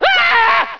scream6.ogg